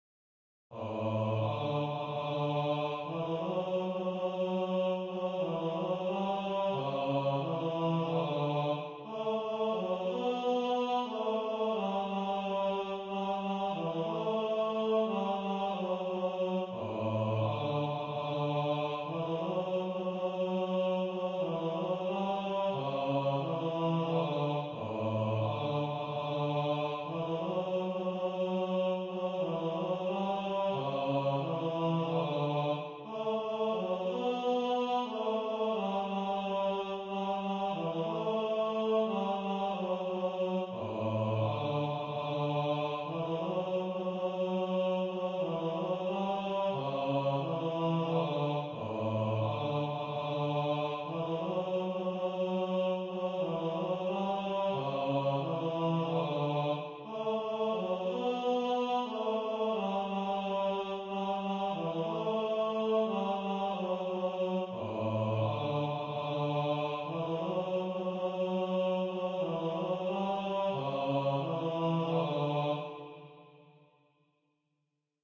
für Gesang, tiefe Stimme solo